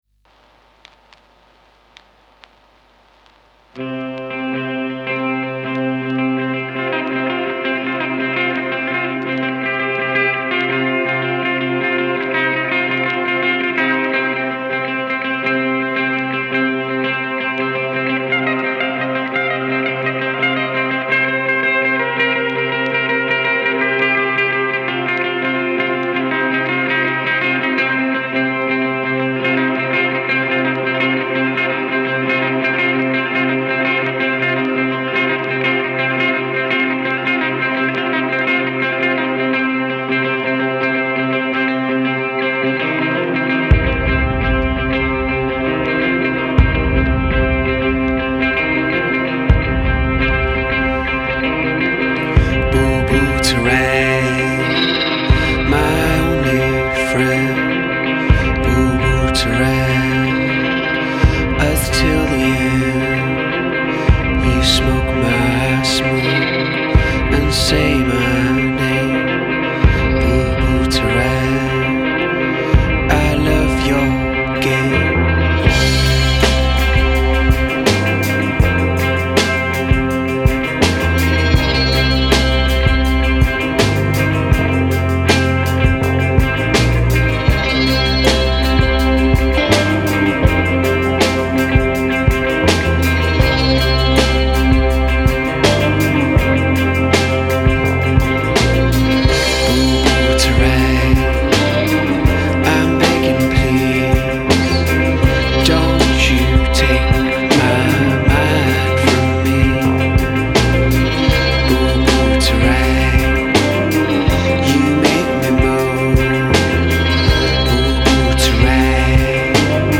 slithering musical painkiller